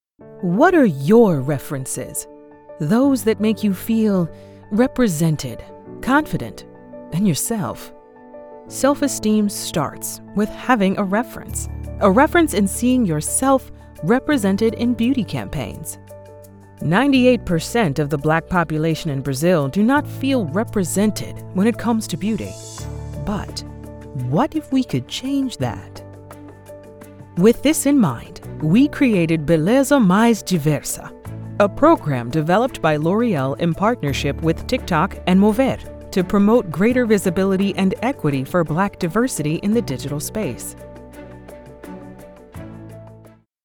Beleza Mais Diversa – Locução em inglês para vídeo corporativo
L_Oreal_Beleza_Mais_Diversa_Ingles_com_Trilha.mp3